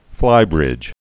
(flībrĭj)